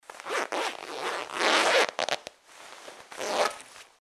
На этой странице собрана коллекция реалистичных звуков работы застёжки-молнии.
Звук молнии куртки